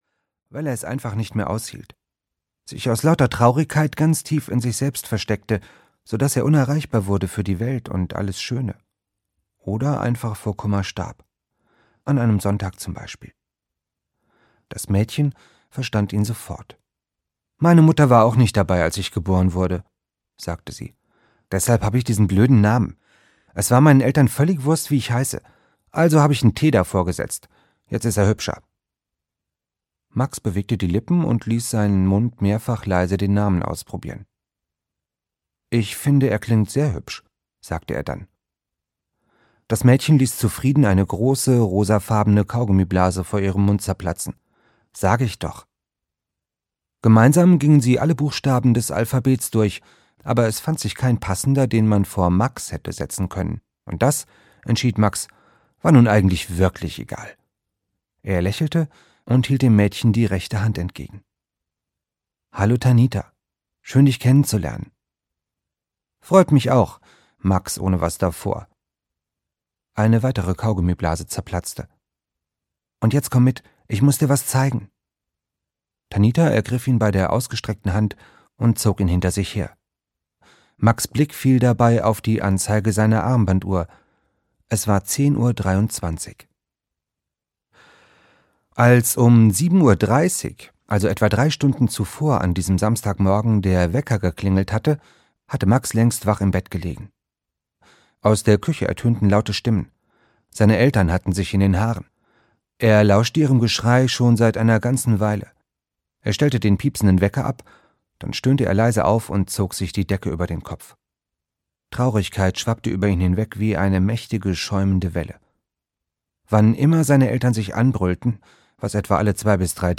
Hörbuch Der mechanische Prinz, Andreas Steinhöfel.